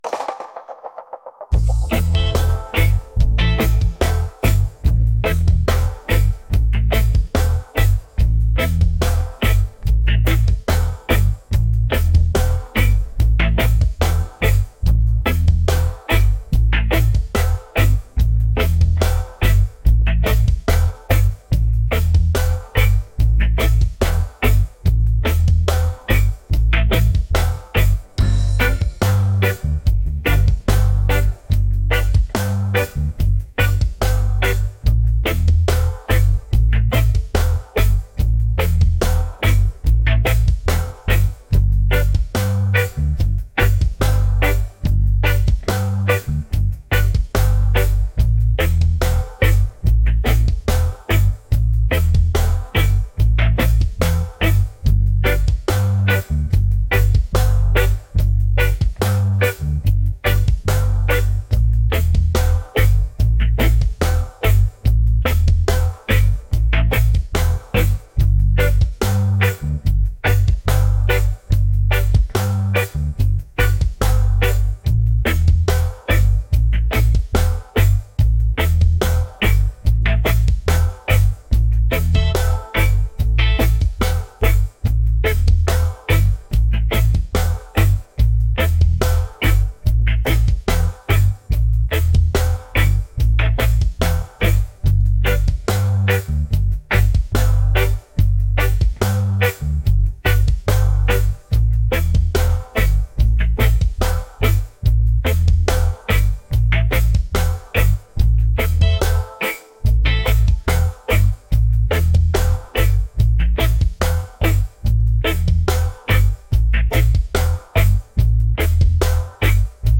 upbeat | energetic | reggae